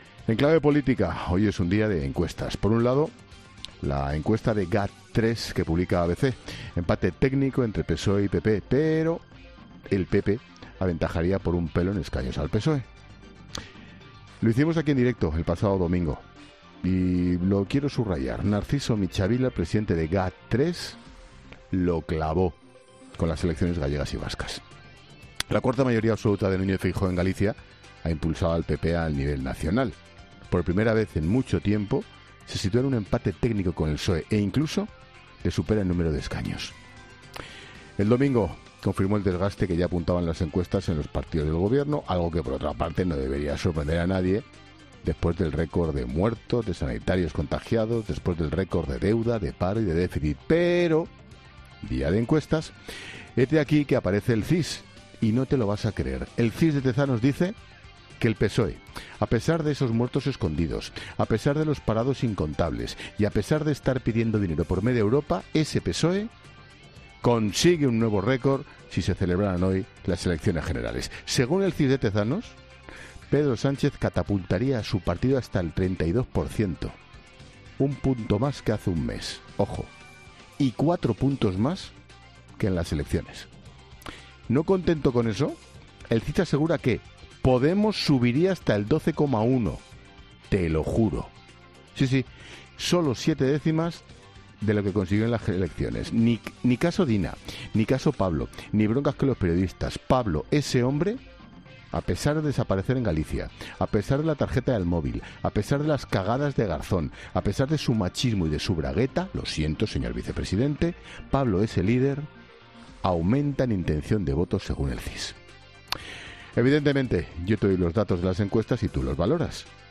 El presentador de La Linterna, Ángel Expósito, ha analizado las primera encuestas en las que el Partido Popular vencería al PSOE, las de GAD3 publicadas por el ABC.